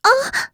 s022_Noraml_Hit.wav